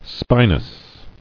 [spi·nous]